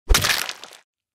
among us (kill) - Bouton d'effet sonore